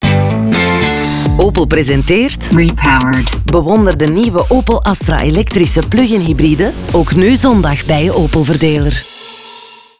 Radio Production: Sonhouse